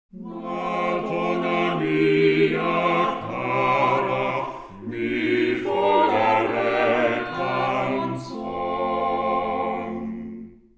Play Quartet
Original (sampled at 16kHz)